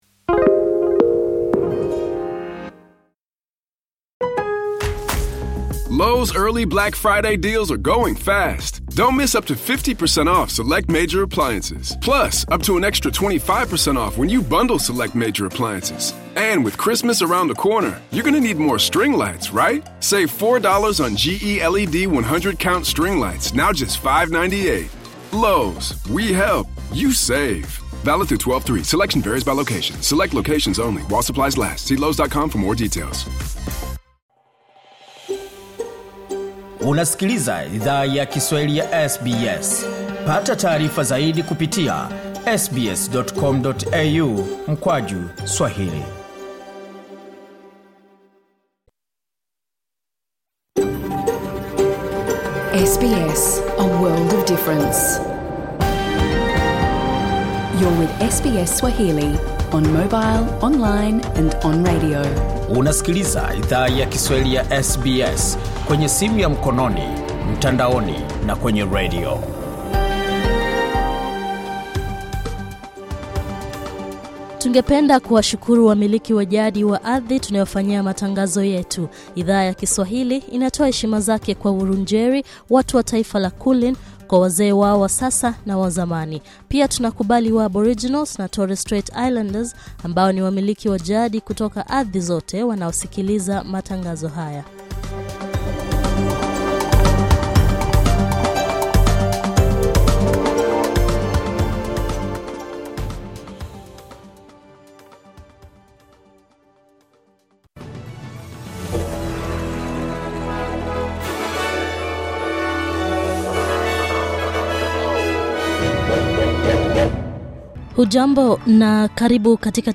Taarifa ya habari:kimbunga fina, mvua, mafuriko na upepo mkali waendelea kuwaathiri wakaazi wa Queensland